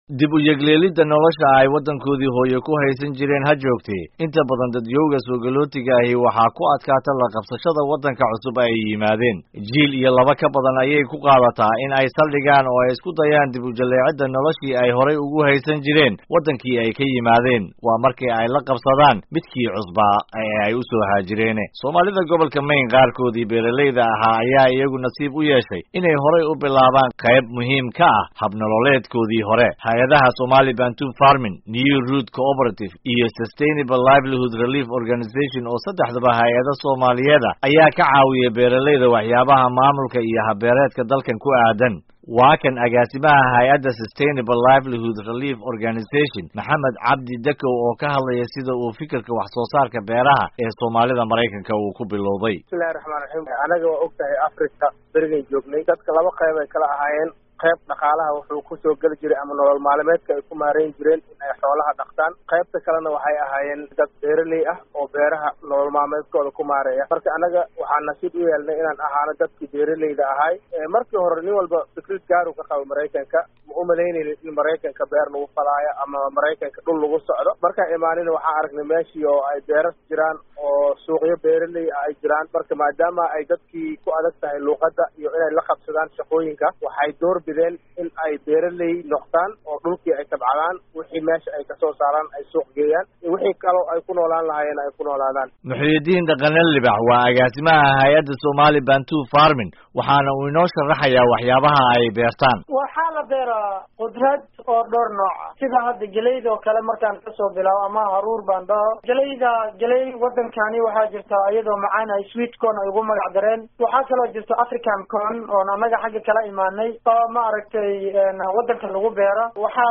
Warbixin: Soomaali Beeraley ku ah Maine